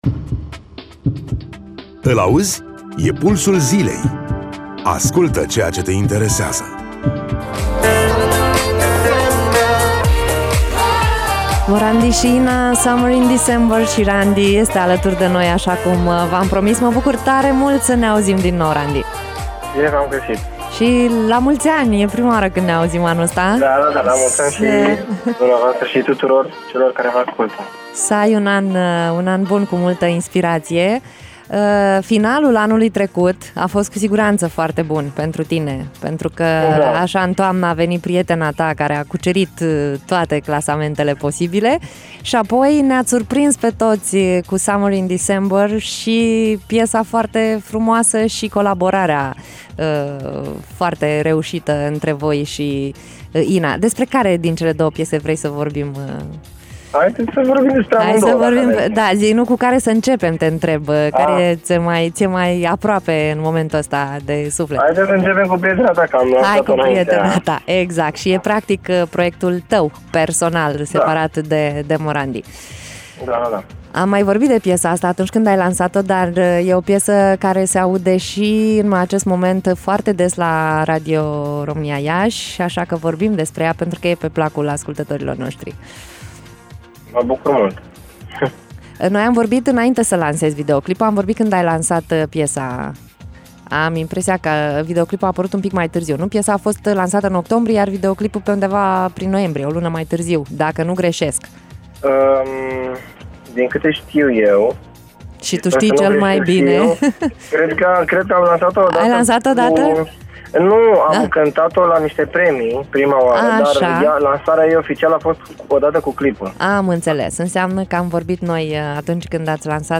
Randi, în direct la Radio Iaşi
interviul